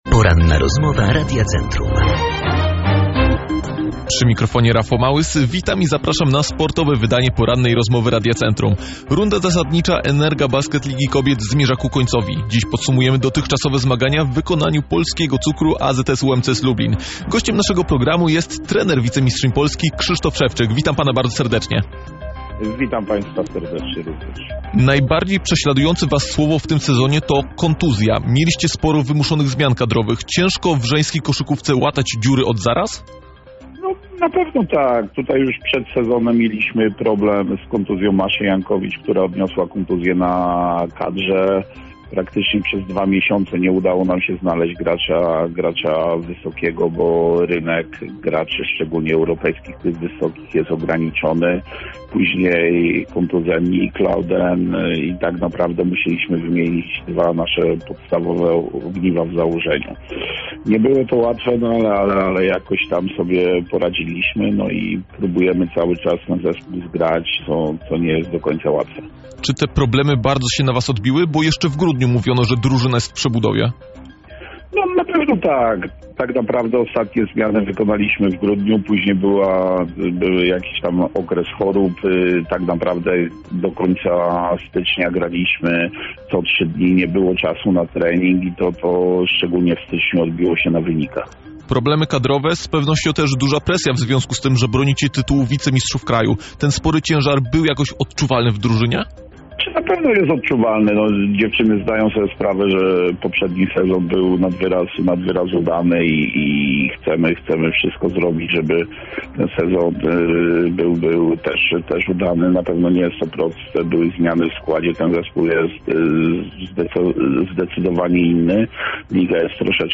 Cala-rozmowa.mp3